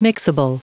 Prononciation du mot mixable en anglais (fichier audio)
Prononciation du mot : mixable